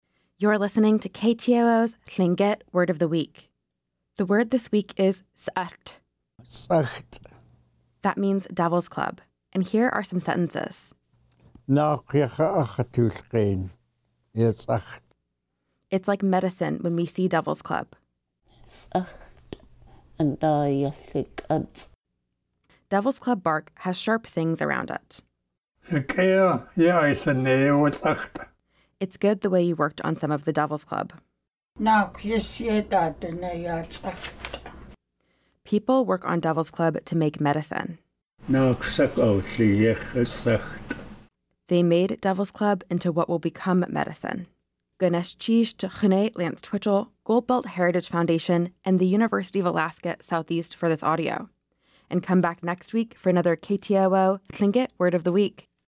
Each week, we feature a Lingít word voiced by master speakers.